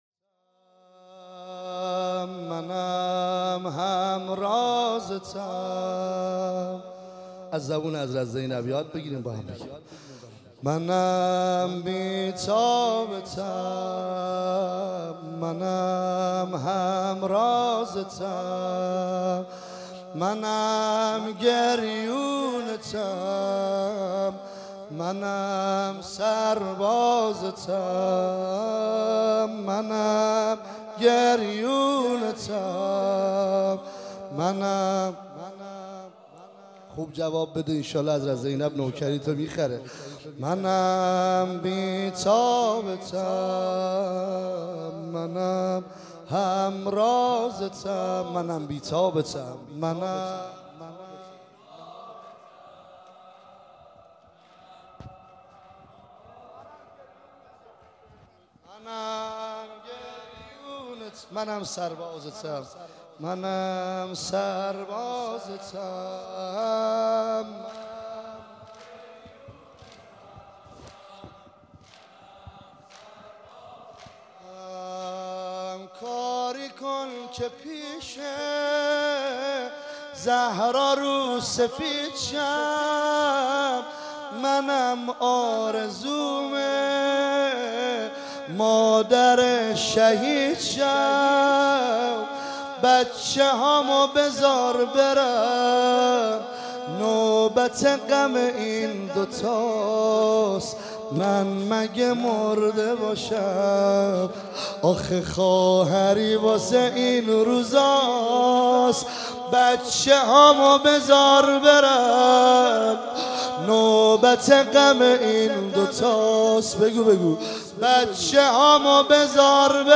عنوان شب چهارم محرم الحرام ۱۳۹۸